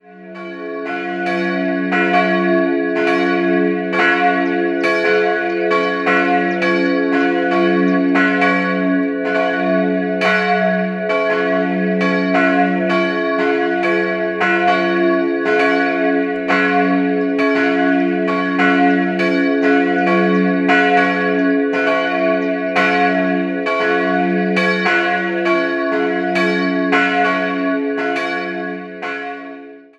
Jahrhunderts erneuert werden. 2-stimmiges Geläut: f'(+)-des'' Die größere Glocke wurde von Hans Glockengießer, die kleine von Christoph Glockengießer, jeweils im 16. Jahrhundert gegossen.